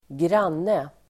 Uttal: [²gr'an:e]